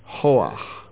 聽 kho'-akh （荊棘）讀音：